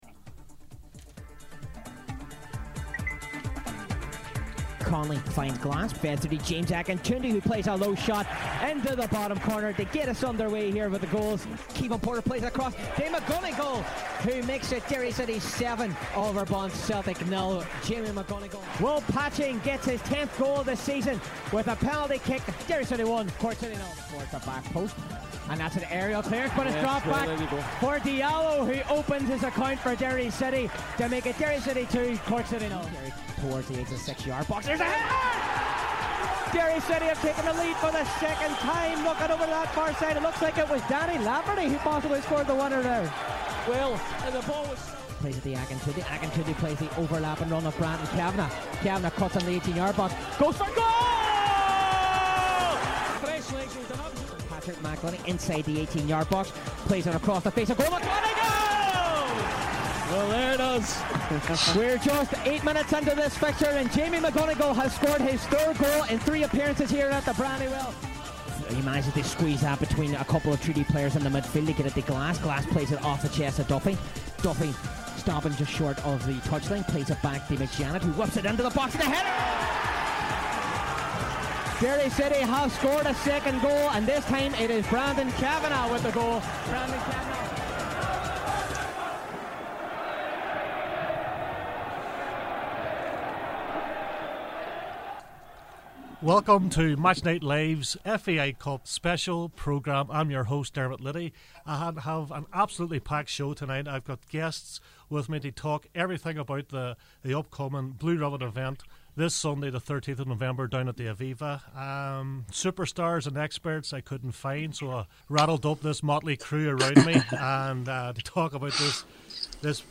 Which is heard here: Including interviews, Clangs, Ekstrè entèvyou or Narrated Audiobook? Including interviews